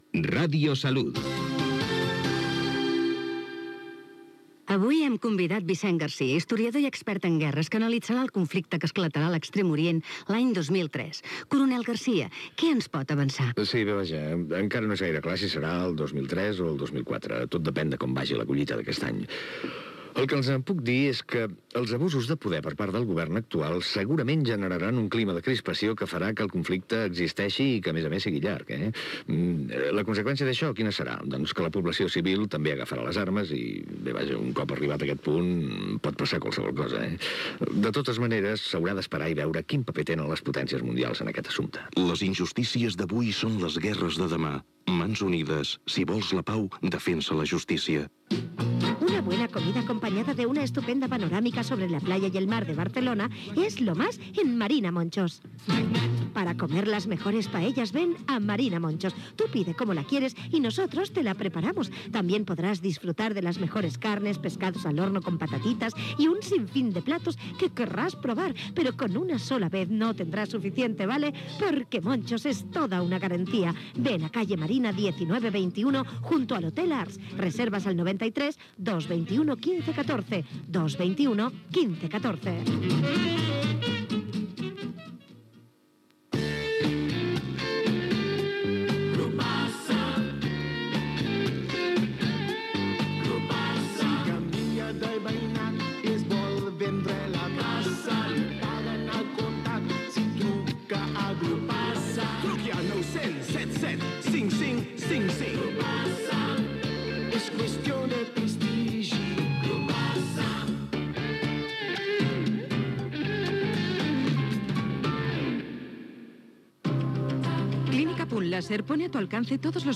Indicatiu de l'emissora amb sintonia d'Onda Cero, publicitat, indicatiu i careta del programa "Supergarcía en Onda Cero"
FM